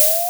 Schuss
ab07_rocket.wav